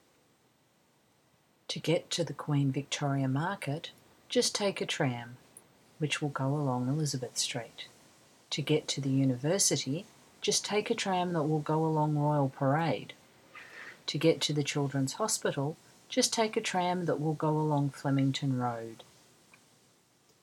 In speech, we can tell the important information in a sentence from where people pause, the speed with which they talk and the emphasis they put on different words. I have made an audio recording of the examples, which you can listen to by clicking on the play button, to help you understand the differences between the examples.
Relative_pronoun_examples.m4a